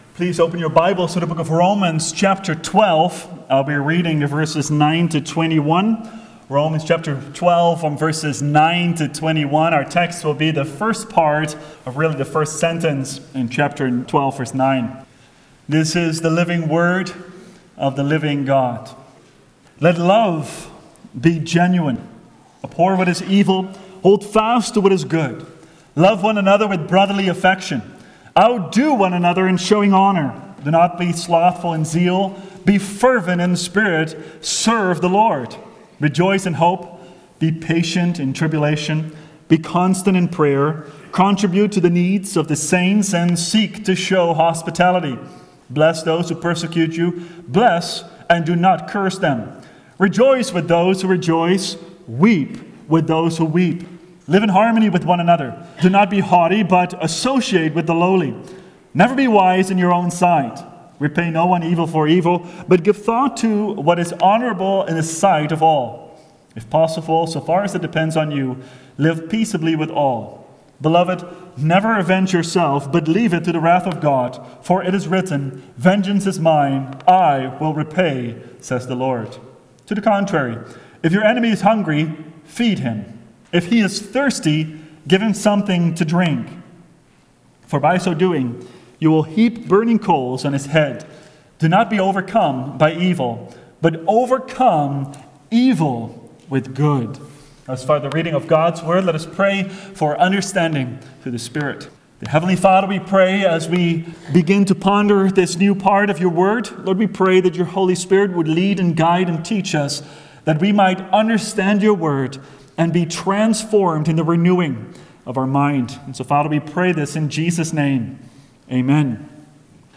Let Love be Genuine – Seventh Reformed Church